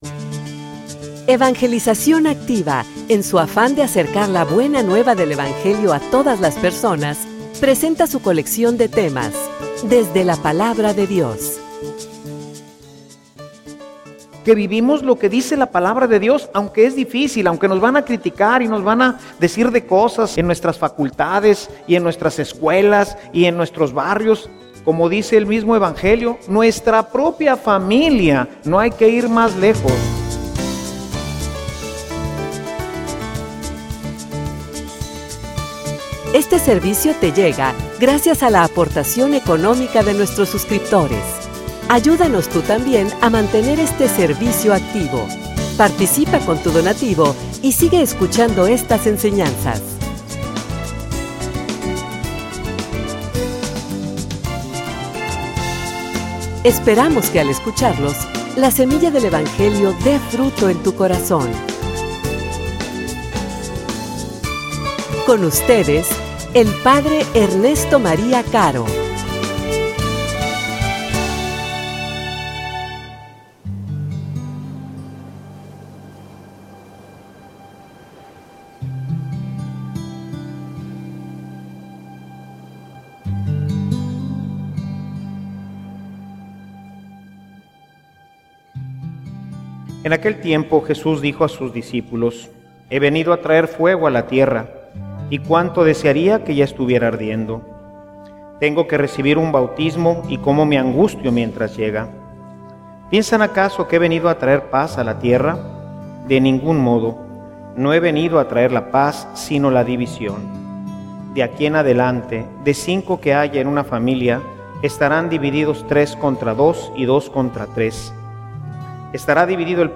homilia_Ser_un_radical.mp3